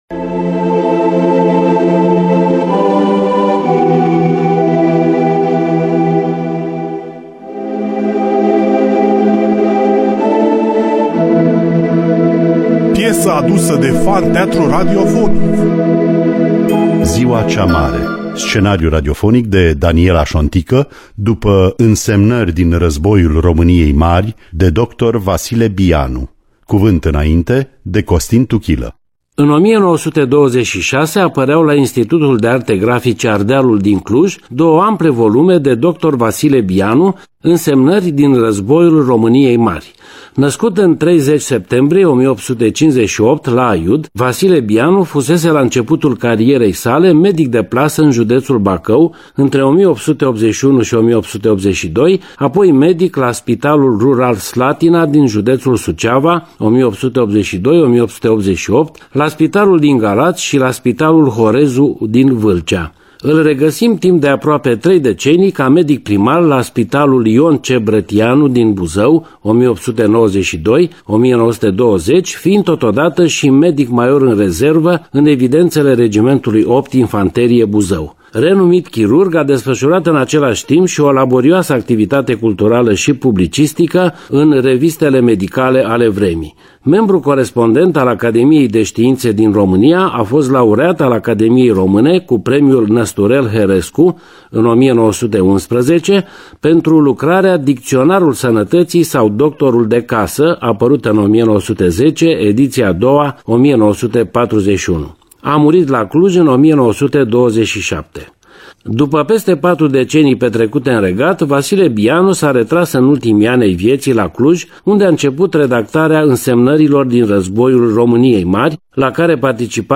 Scenariu radiofonic
Muzica originală și regia muzicală